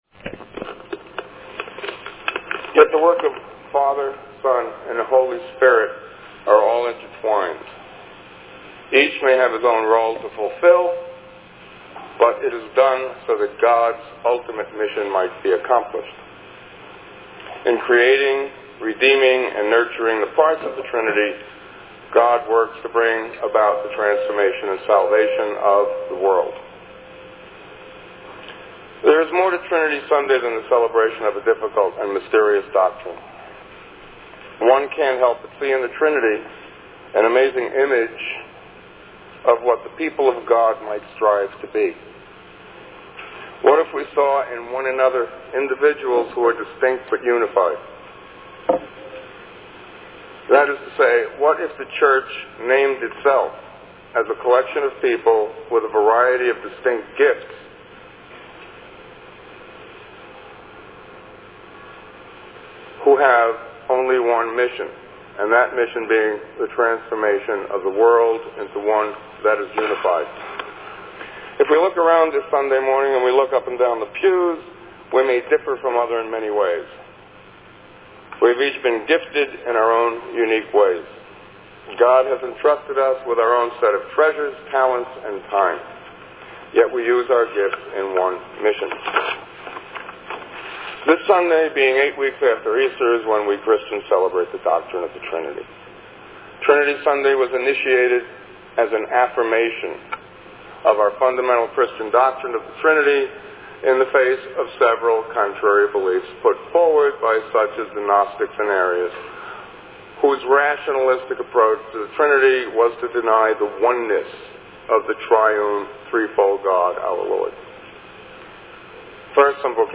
Trinity Sunday Sermon.mp3